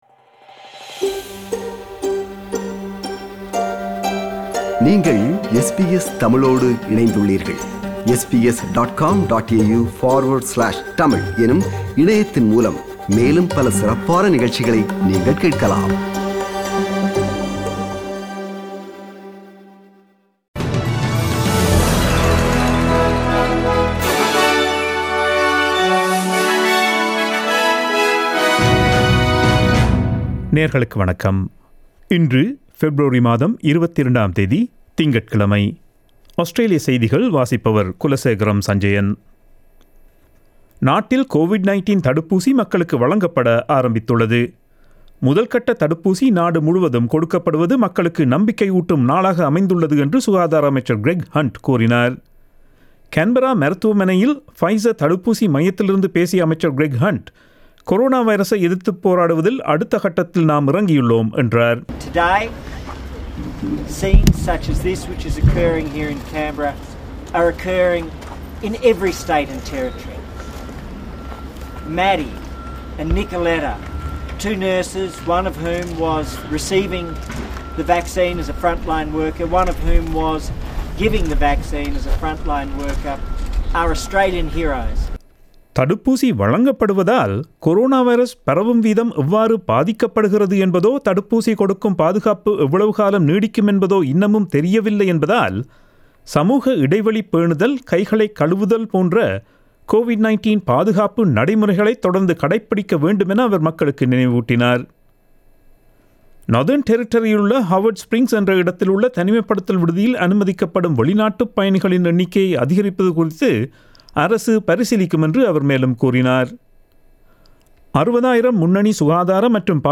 Australian news bulletin for Monday 22 February 2021.